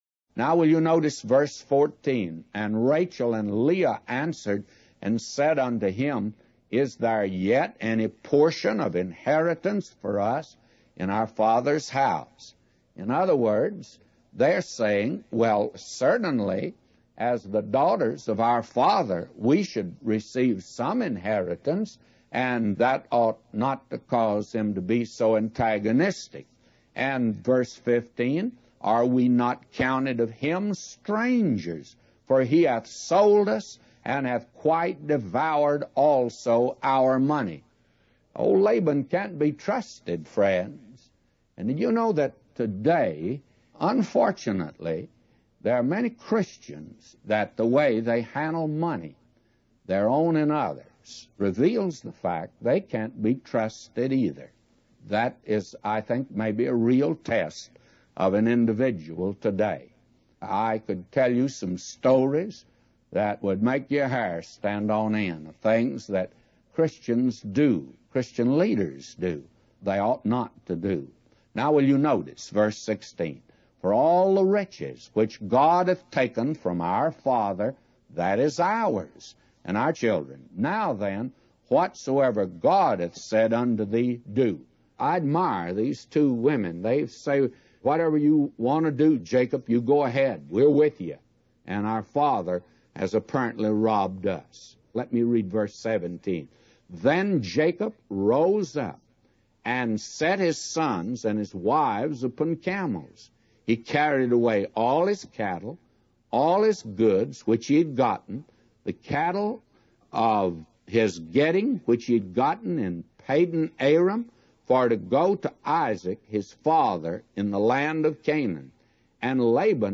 A Commentary By J Vernon MCgee For Genesis 31:14-999